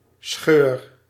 The Scheur (Dutch pronunciation: [sxøːr]